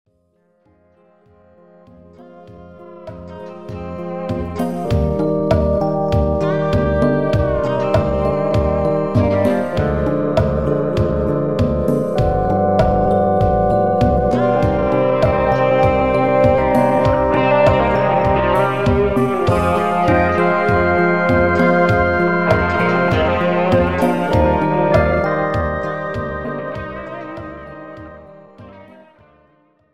This is an instrumental backing track cover.